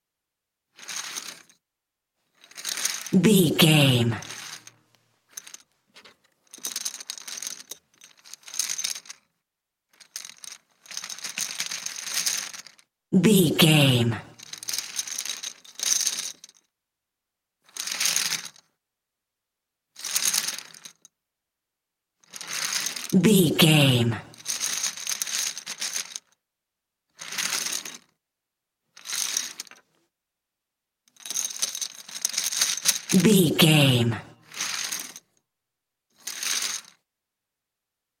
Casino 50 chips table movement
Sound Effects
foley